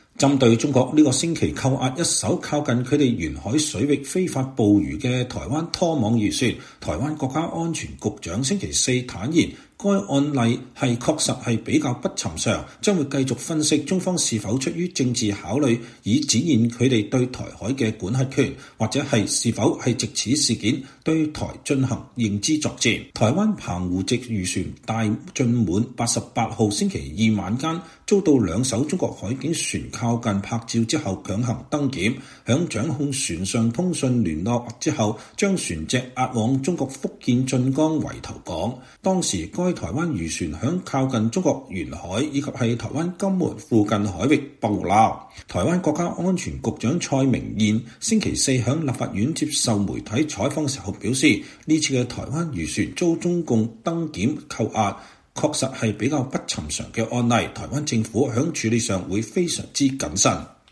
台灣海巡署分署長廖德成在台北舉行的記者會上談一艘漁船7月2日被中國船隻攔截事件。 (2024年7月3日)